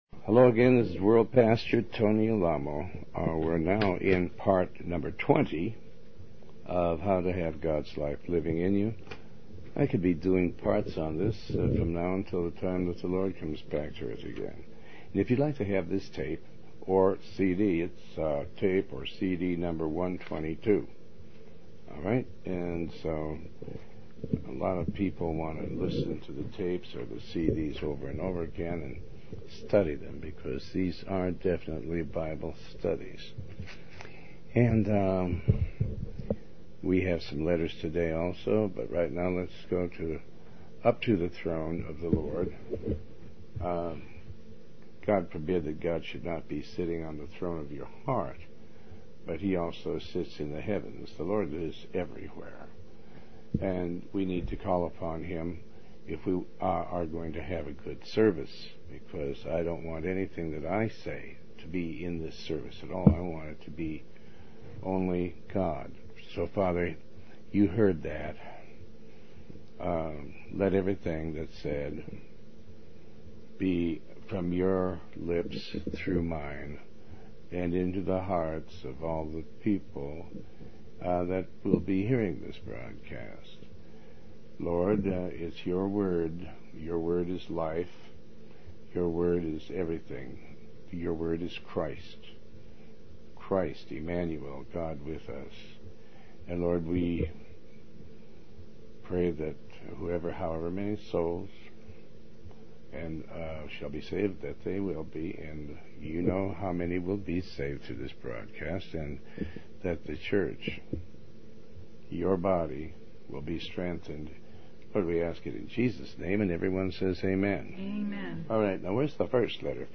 Talk Show Episode, Audio Podcast, Tony Alamo and Ep122, How To Have Gods Life Living In You, Part 20 on , show guests , about How To Have Gods Life Living In You, categorized as Health & Lifestyle,History,Love & Relationships,Philosophy,Psychology,Christianity,Inspirational,Motivational,Society and Culture